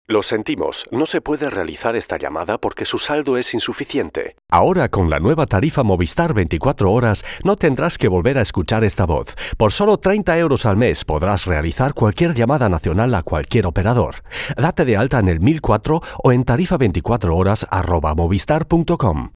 Volle, warme, präsente, moderative und rassige spanische Voice-Over-Stimme
kastilisch
Sprechprobe: Sonstiges (Muttersprache):
Experienced spanish Voice-Over Actor; Full, warm, prominent voice